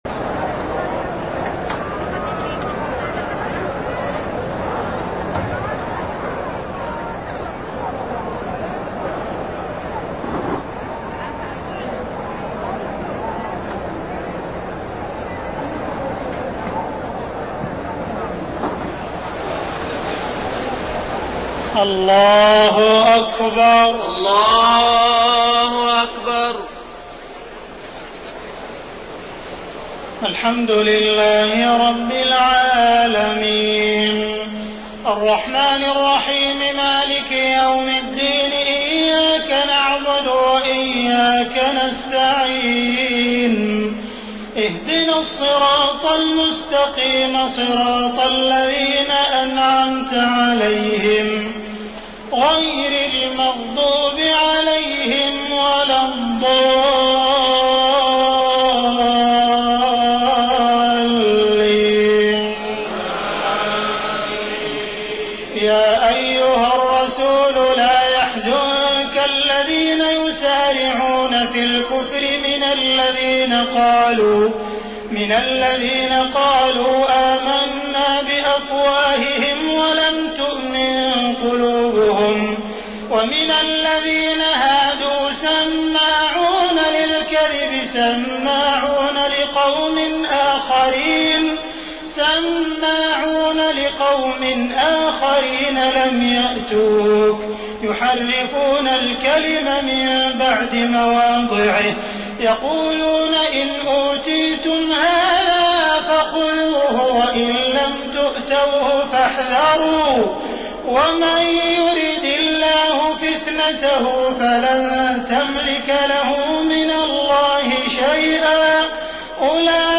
تهجد ليلة 26 رمضان 1433هـ من سورة المائدة (41-81) Tahajjud 26 st night Ramadan 1433H from Surah AlMa'idah > تراويح الحرم المكي عام 1433 🕋 > التراويح - تلاوات الحرمين